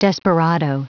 Prononciation du mot desperado en anglais (fichier audio)
Prononciation du mot : desperado